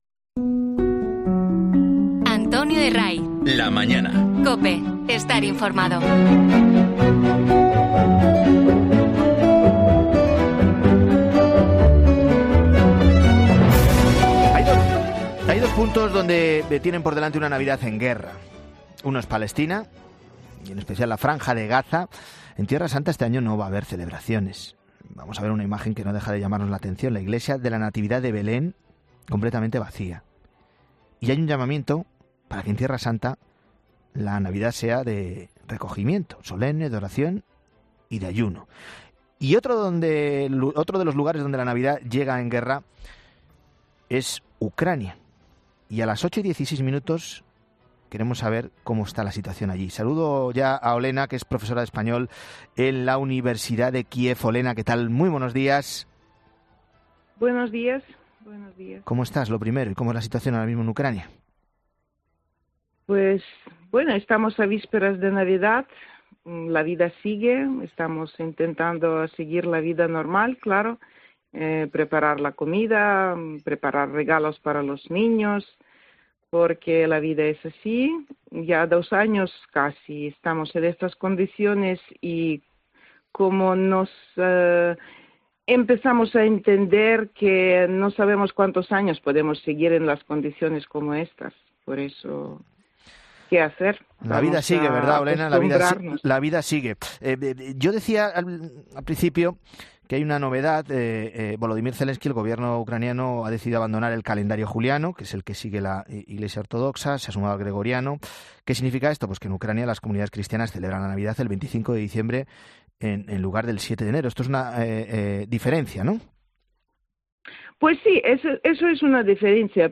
y atiende la llamada de La Mañana Fin de Semana para contarnos cómo se preparan para estas fechas tan señaladas.